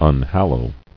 [un·hal·low]